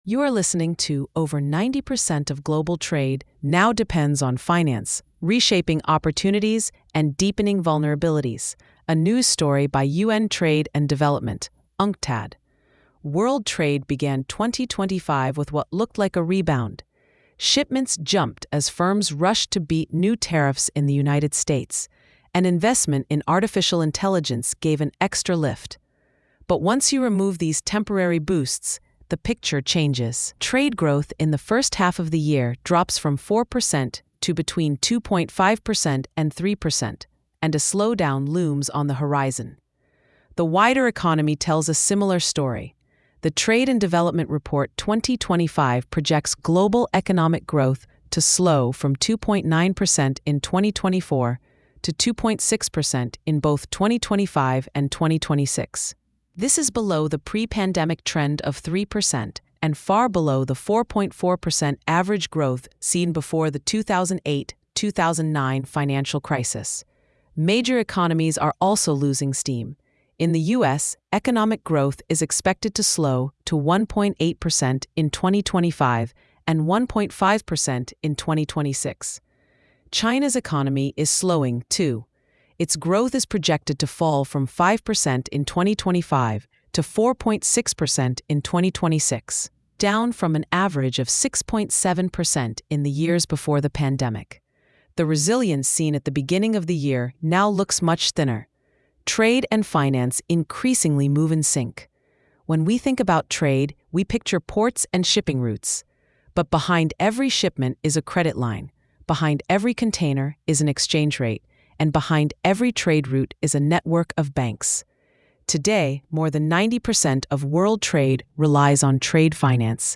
Narrated by AI.